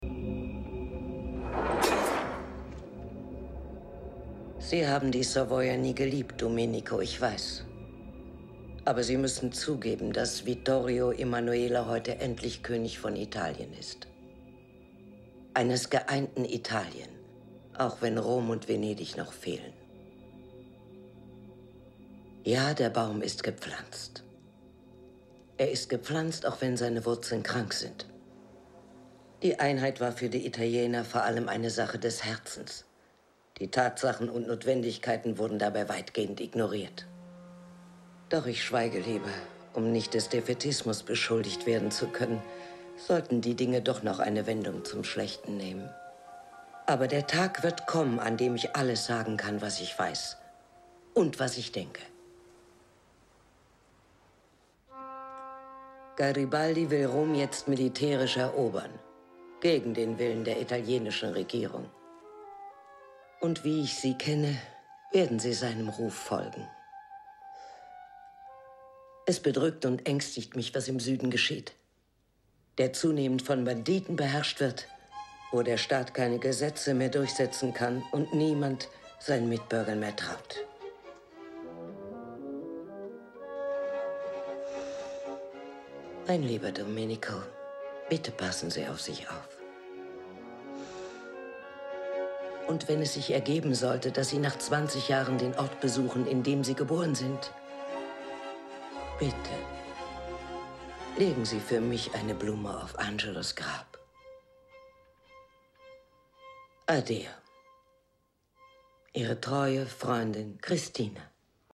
Synchronschauspielerin
Hier ein paar SPRACHPROBEN